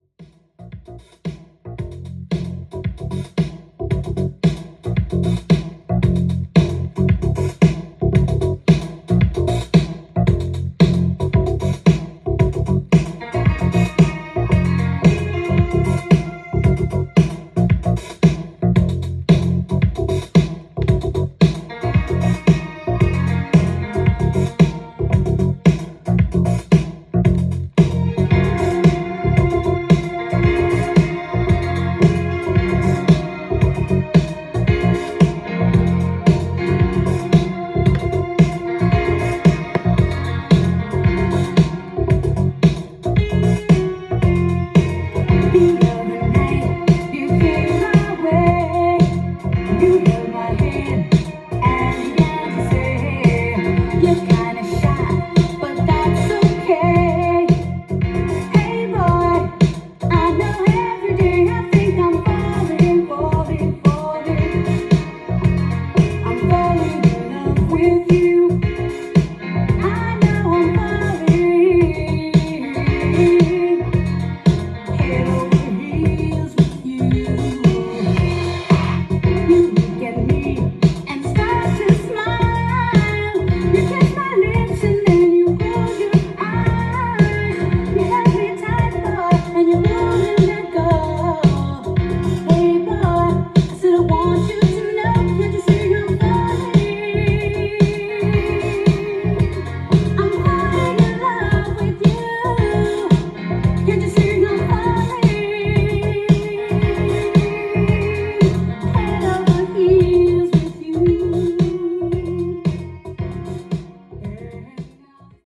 ジャンル：FUNK-DANCE SOUL
店頭で録音した音源の為、多少の外部音や音質の悪さはございますが、サンプルとしてご視聴ください。